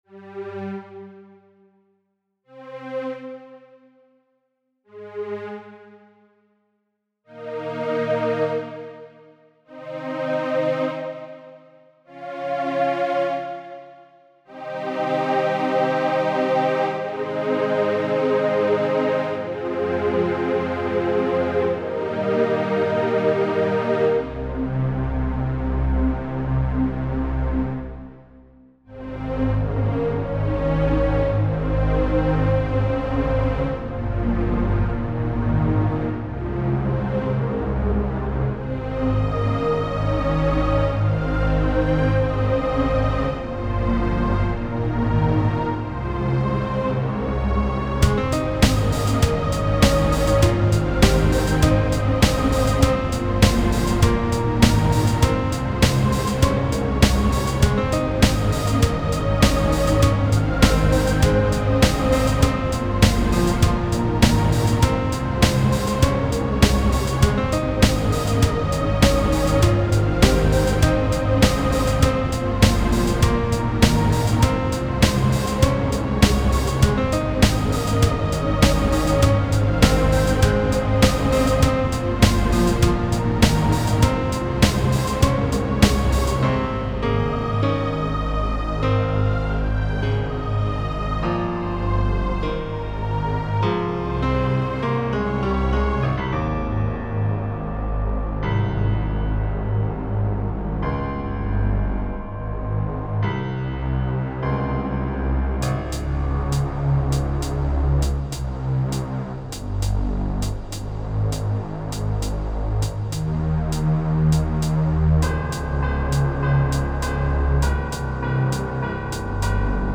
Description: This recording was sequenced in FruityLoops 3.4, and uses an older VST synthesizer for strings, and IK Multimedia's Sampletank for the piano. Percussion was done with various samples in my collection.